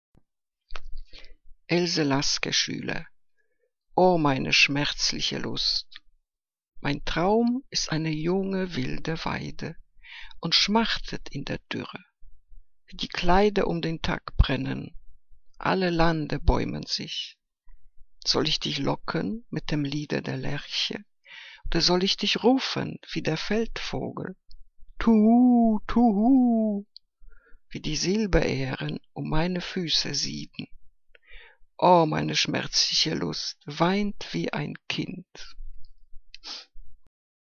Liebeslyrik deutscher Dichter und Dichterinnen - gesprochen (Else Lasker-Schüler)